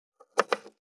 523野菜切る,咀嚼音,ナイフ,調理音,まな板の上,料理,
効果音厨房/台所/レストラン/kitchen食器食材